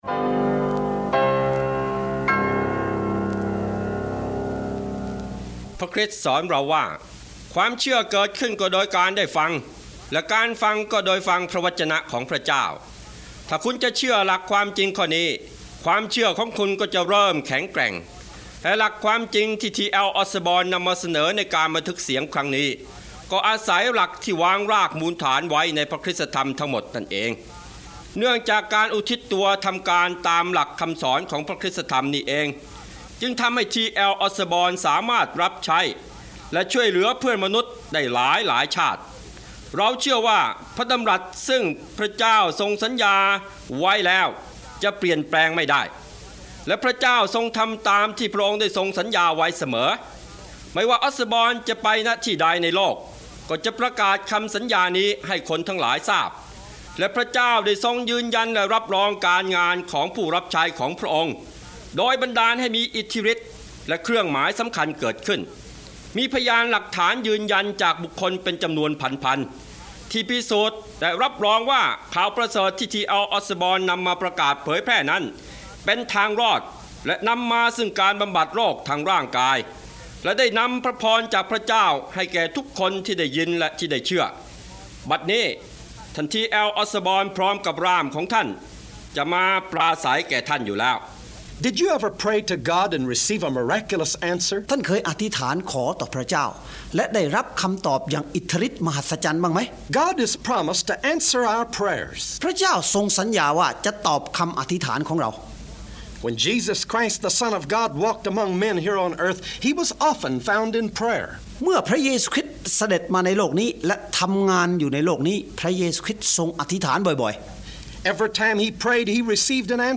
9-คำอธิษฐานและปาฏิหาริย์-Prayer-And-Miracles-Eng-THAI.mp3